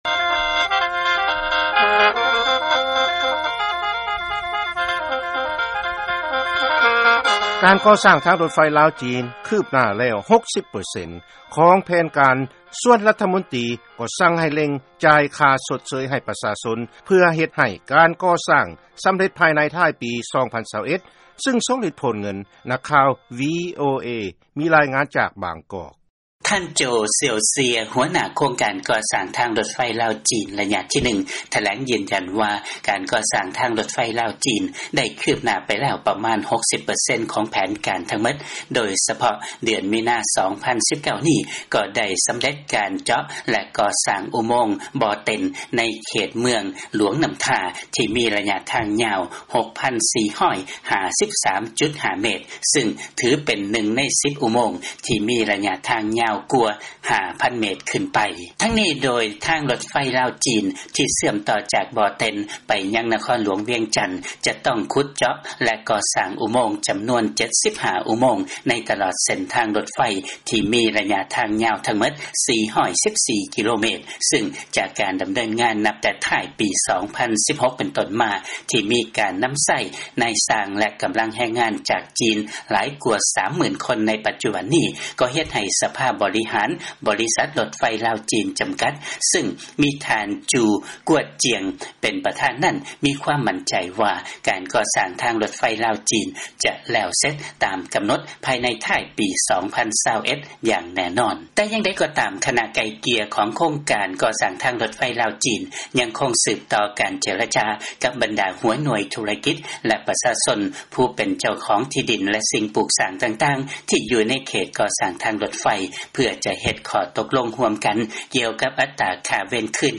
ເຊີນຟັງລາຍງານ ການກໍ່ສ້າງທາງລົດໄຟ ລາວ-ຈີນ ຄືບໜ້າແລ້ວ 60 ເປີເຊັນ ຂອງແຜນການ ທີ່ວາງໄວ້